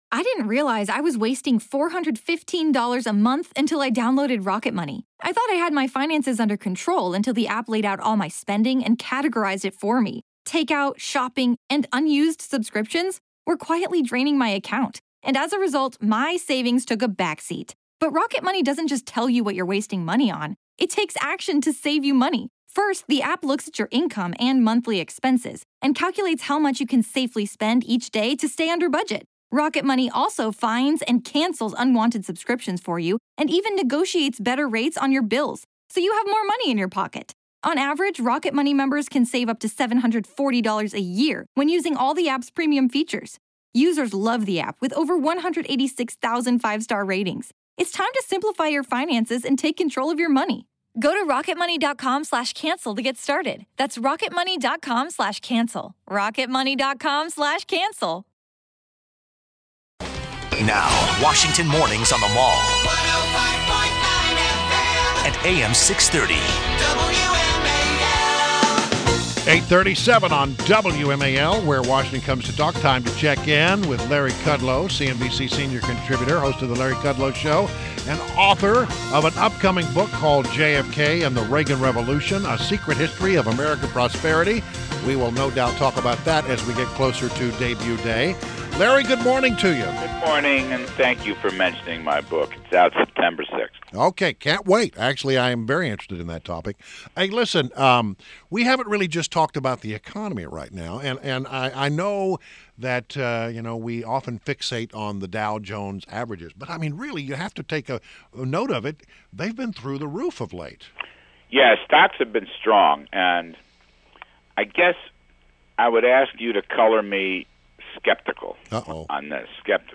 WMAL Interview - LARRY KUDLOW - 08.16.16